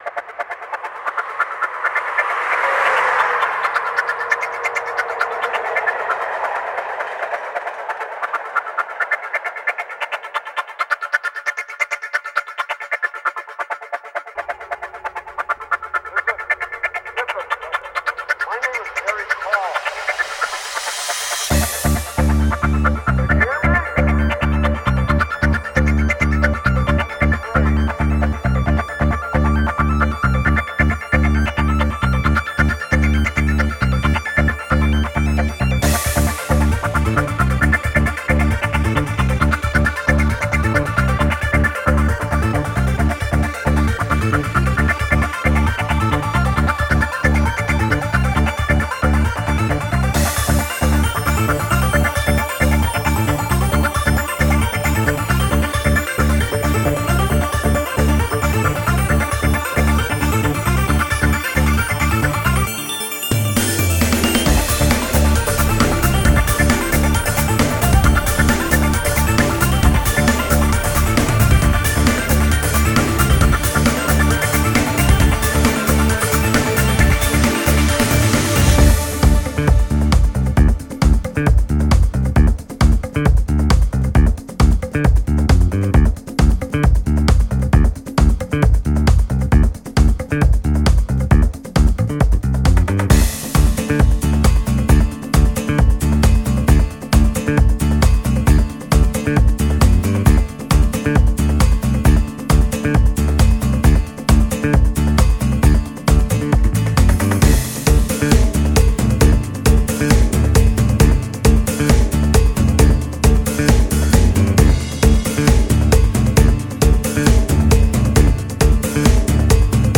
trance_music
electronic_music